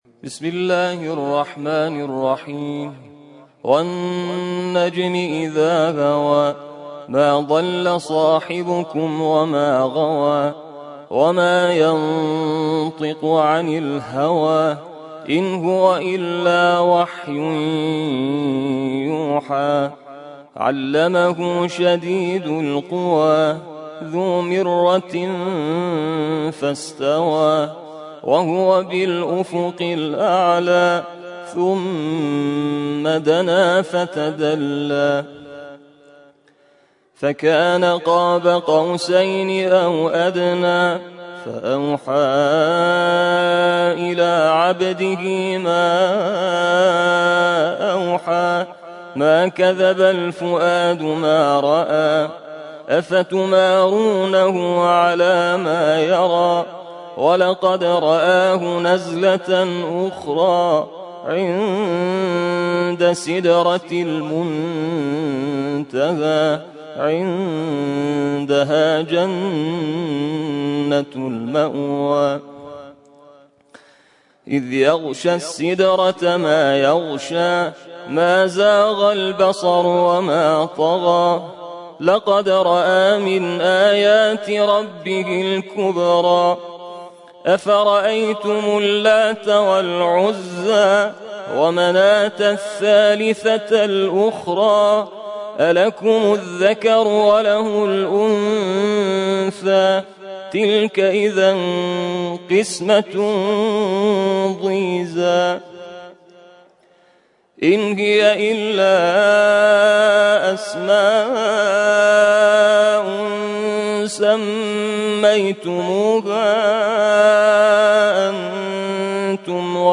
ترتیل خوانی جزء ۲۷ قرآن کریم - سال ۱۴۰۰